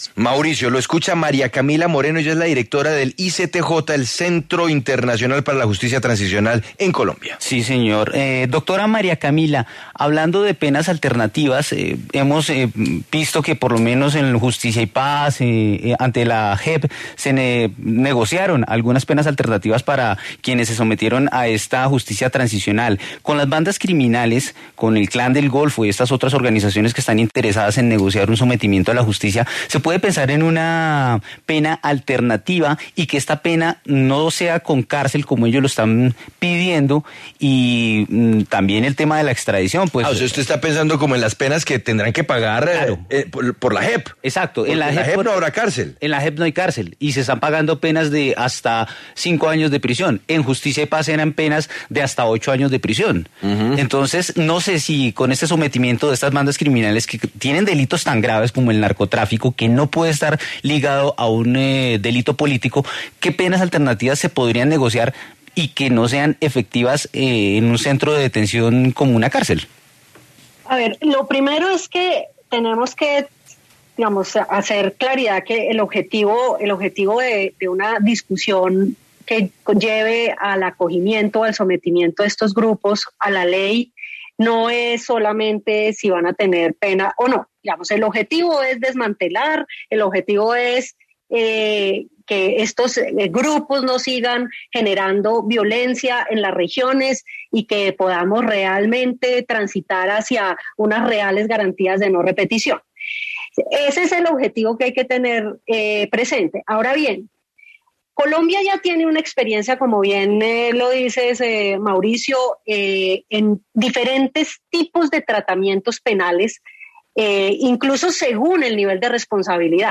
Tema del DíaEspeciales